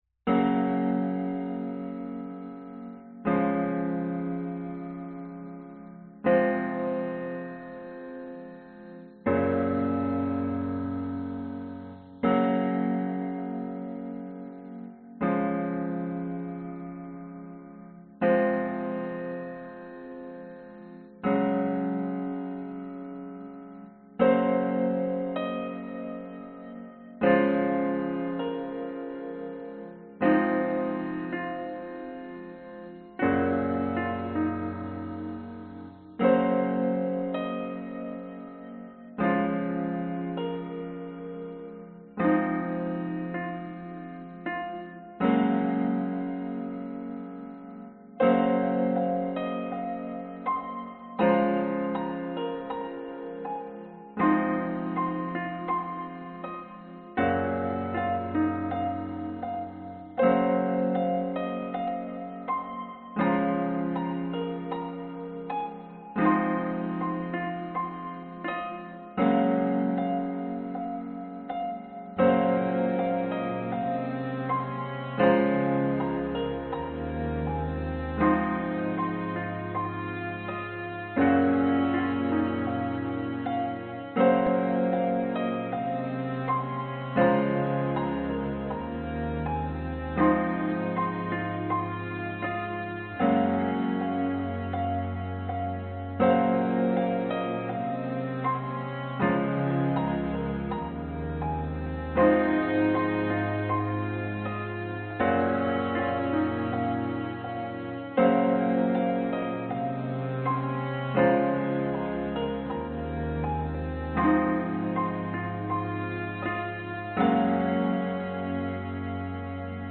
Tag: 器乐 钢琴 小提琴 环境 寒意